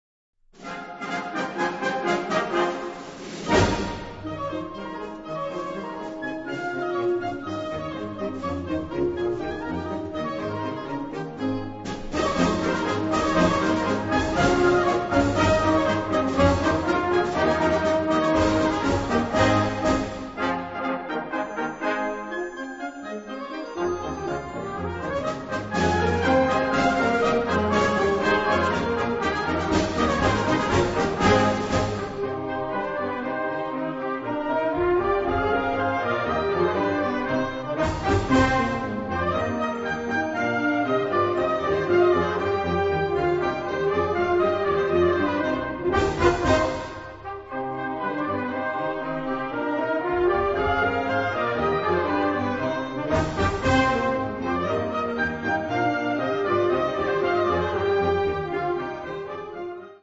Kategorie Blasorchester/HaFaBra
Unterkategorie Suite
Besetzung Ha (Blasorchester)